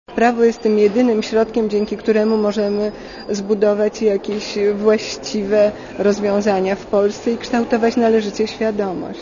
Posłuchaj komentarza Marii Szyszkowskiej